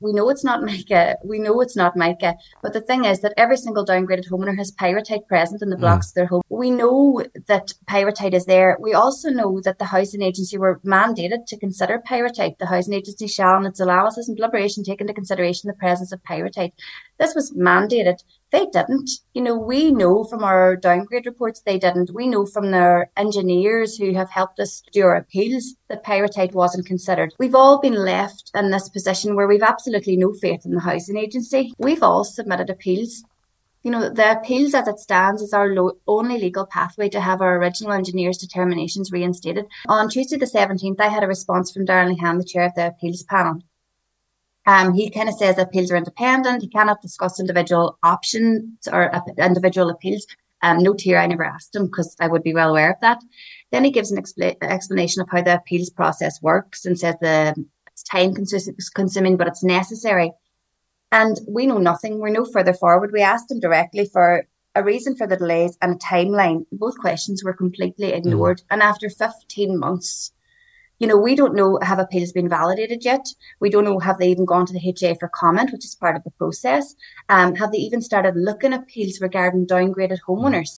on today’s None til Noon Show